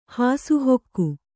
♪ hāsu hokku